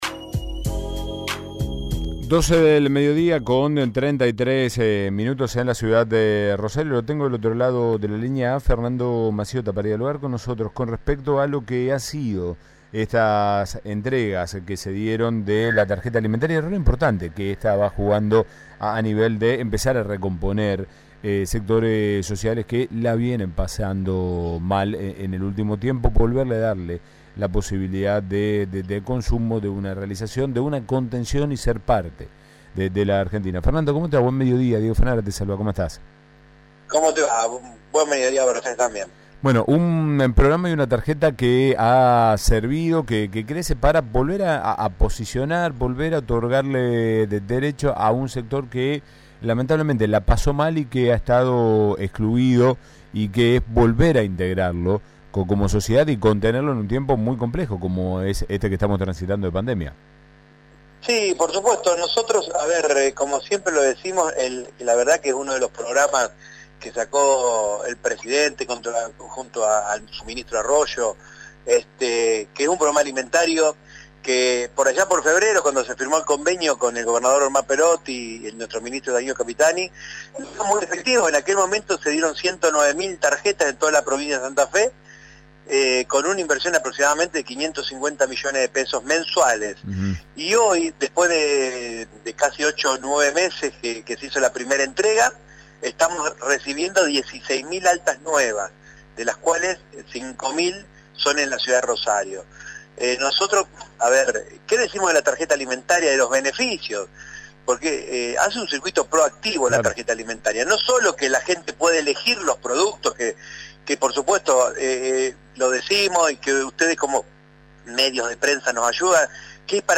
Comenzó la entrega de la Tarjeta Alimentar para 16.000 nuevos beneficiarios en toda la provincia. El secretario de desarrollo territorial de Santa Fe, Sergio Mazziota, habló al respecto con AM 1330.